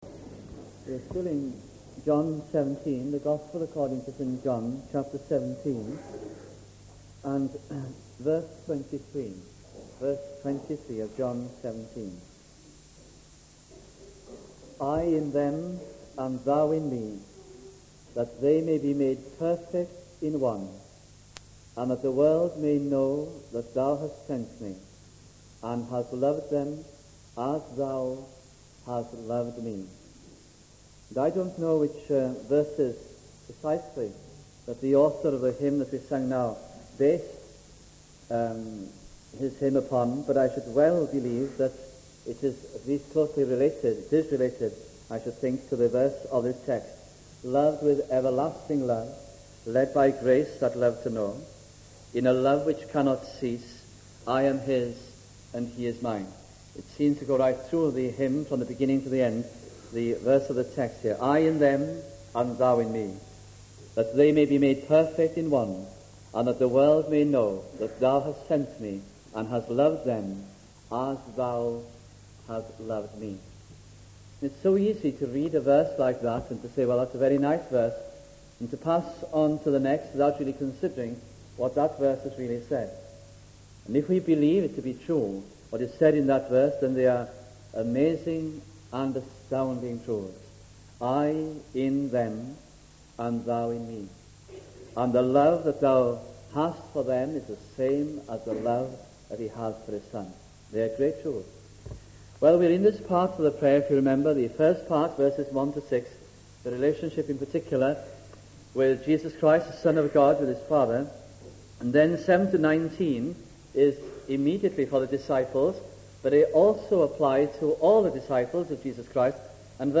» John 17 Series - The Lord's Prayer 1971 - 1972 » Please note that due to missing parts of the historic audit of recordings this series is incomplete » We also regret that a few sermons in this series do not meet the Trust's expectations of the best sound quality.